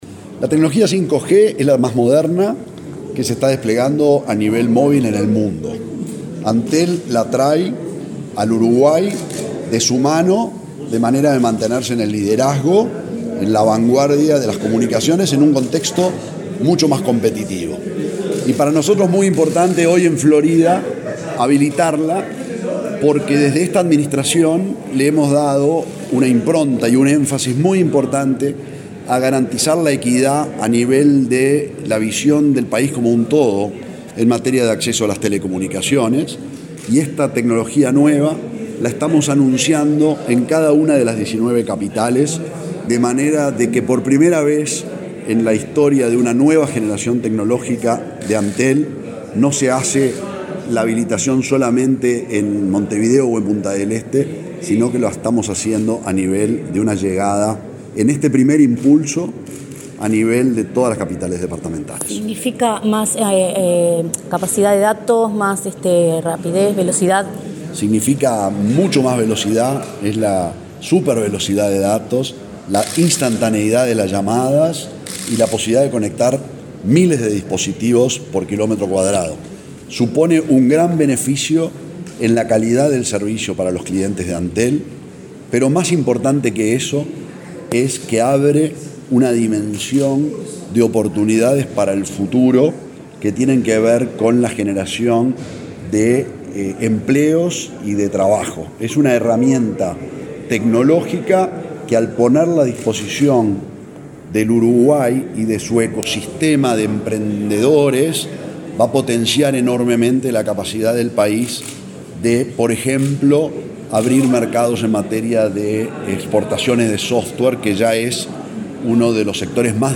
Declaraciones del presidente de Antel, Gabriel Gurméndez | Presidencia Uruguay
El presidente de Antel, Gabriel Gurméndez, dialogó con la prensa en Florida, donde inauguró la nueva tecnología de quinta generación (5G).